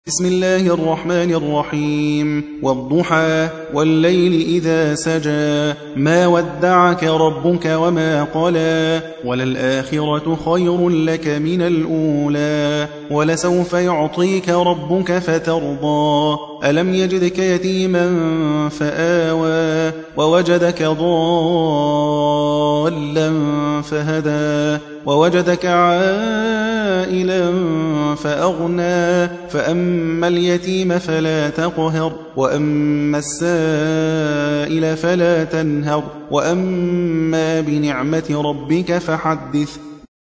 93. سورة الضحى / القارئ